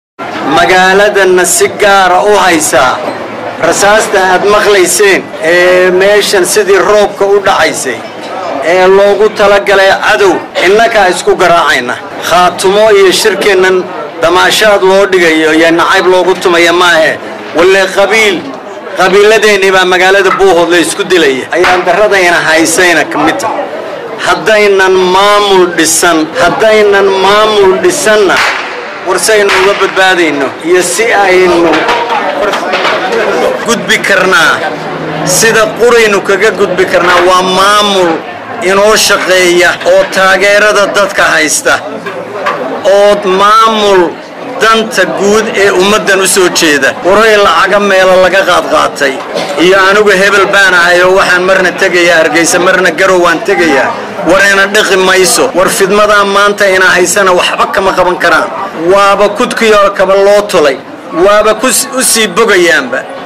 Isagoo ku sugnaa goob laga maqlaayay rasaas Xooggan ayuu sheegay Cali Khaliif in waxa Magaalada isku diriraayo ay yihiin dadkii Khaatumo oo la isku diray.